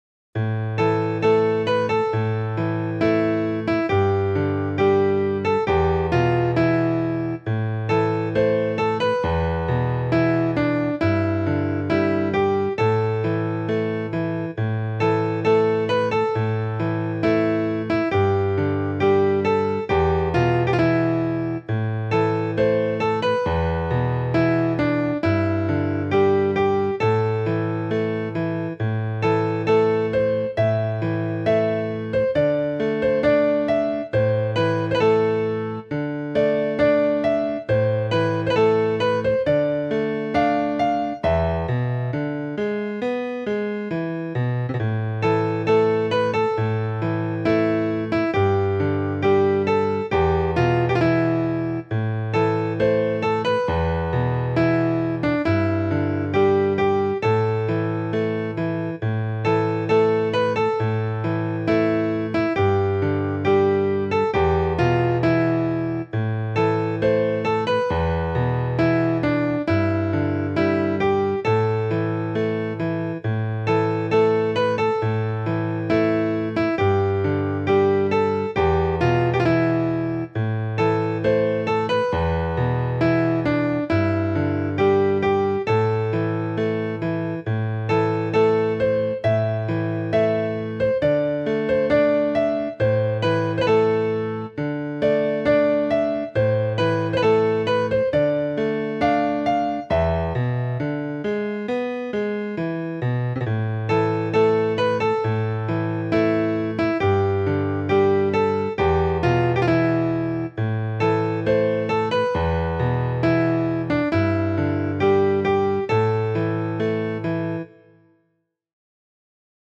I never particularly liked the truimphal feel of the setting in the hymnbook, but this intended tune changes the entire feel of the song -- it was meant to be mournful, not celebratory.
Anyway, I made a simple piano arrangement based on that video, and here it is.